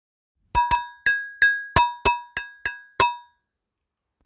ベル型の鉄製楽器ラベオ、ダブルタイプです。
腰のあるサウンド、使える鉄楽器です。
素材： 鉄